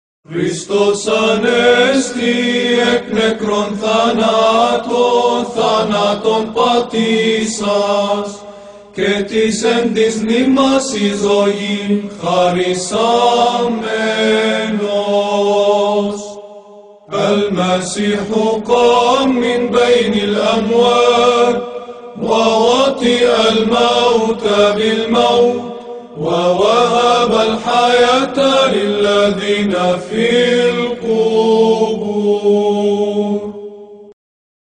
ترانيم فصحيّة
Byzantine Melkite Hymn Christ is risen.flv_.mp3